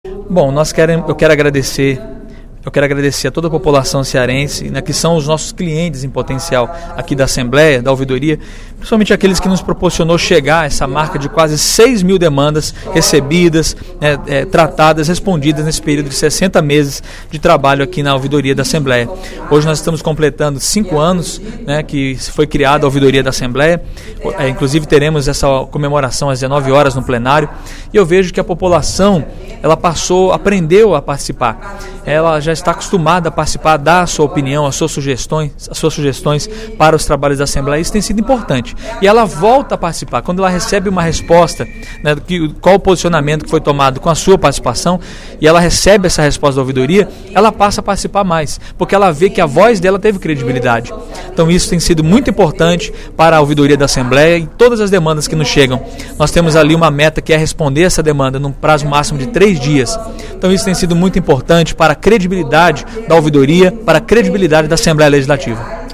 O ouvidor da Assembleia Legislativa, deputado Ronaldo Martins (PRB), destacou, em pronunciamento na tribuna da Assembleia Legislativa nesta sexta-feira (25/05), os cinco anos de funcionamento da Ouvidoria Parlamentar.
Em aparte, o deputado Sérgio Aguiar (PSB) disse que a Ouvidoria Parlamentar “desenvolve um trabalho exemplar, desde 2007”.